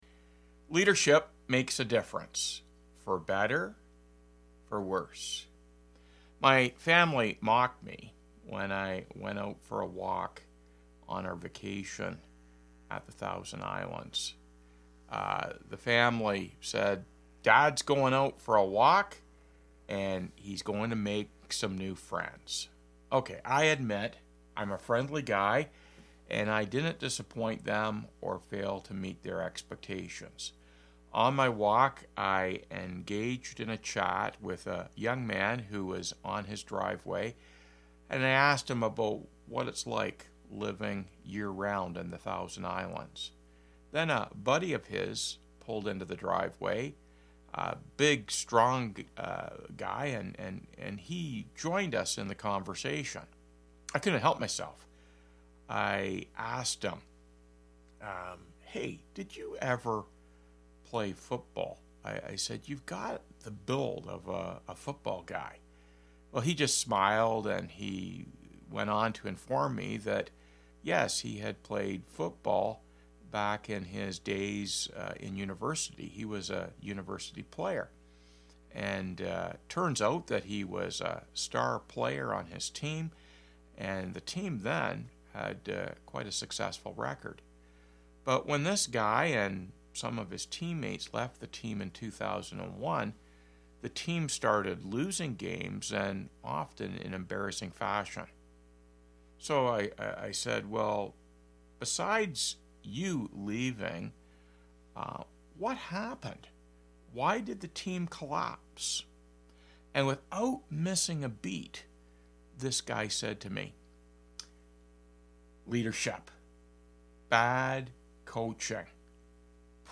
Weekly Sermons - Byron Community Church